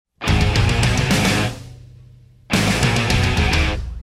• Качество: 128, Stereo
гитара
без слов
classic metal